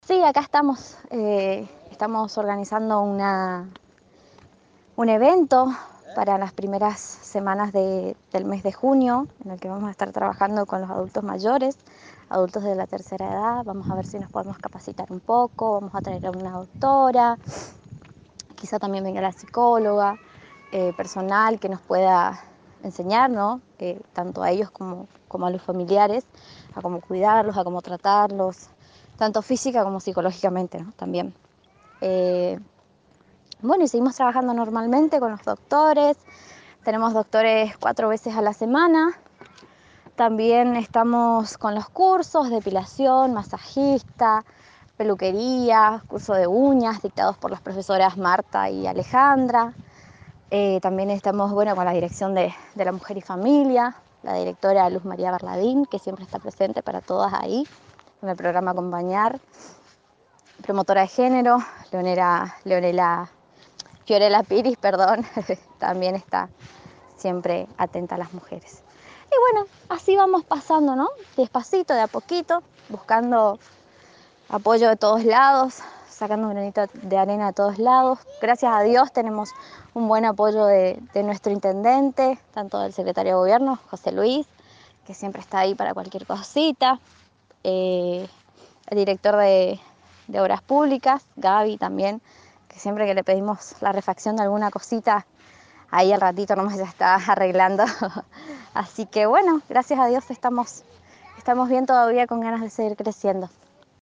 En un diálogo telefónico en exclusiva para la Agencia de Noticias Guacurarí